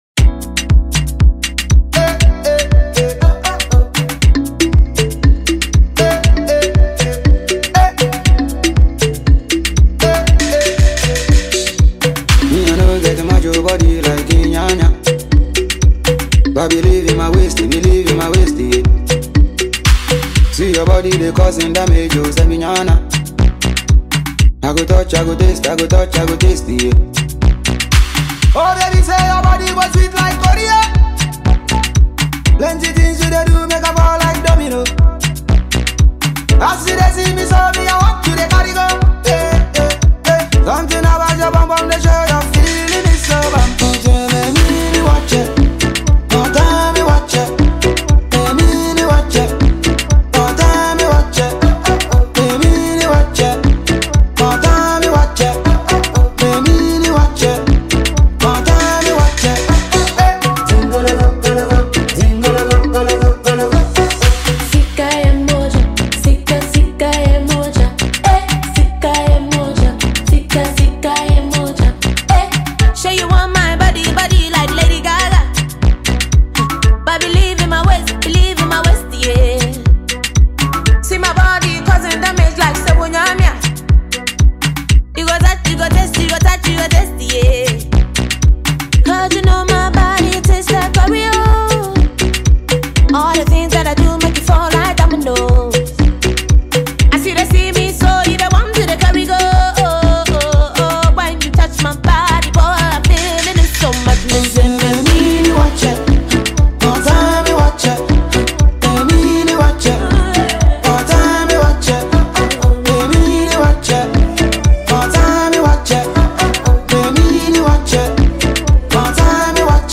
soothing